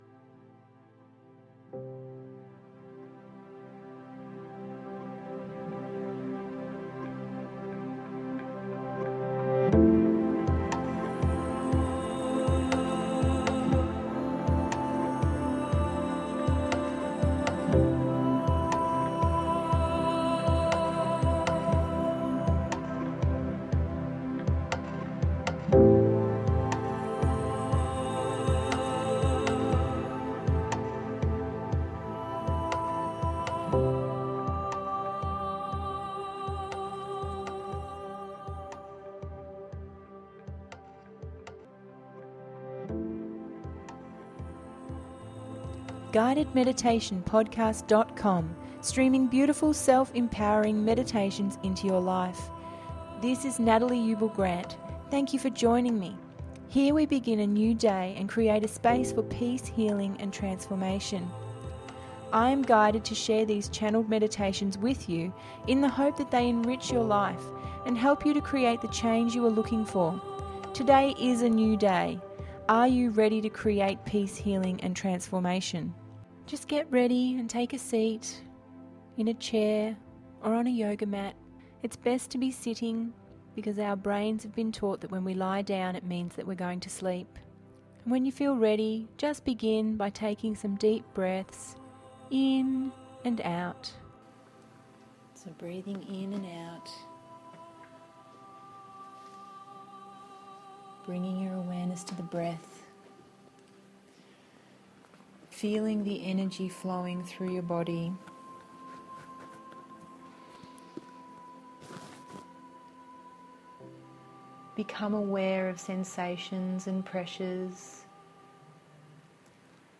Meditation duration approx. 23 mins